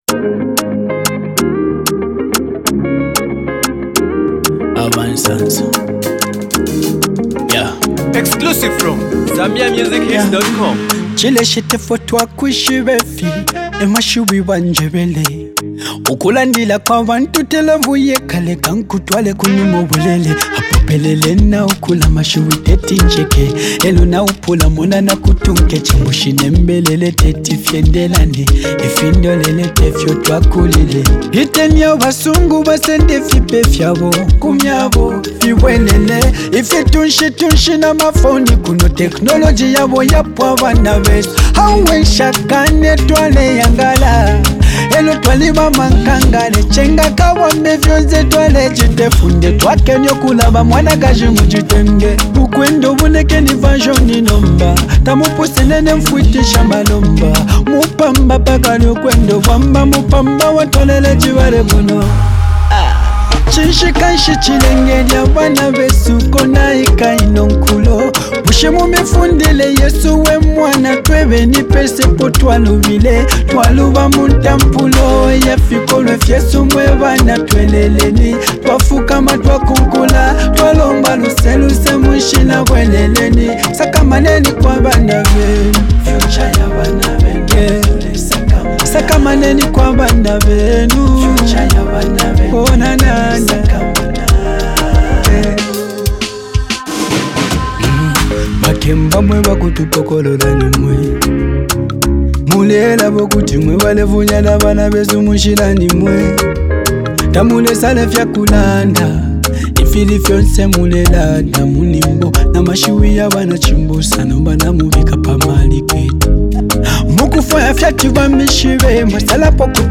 a new powerful melody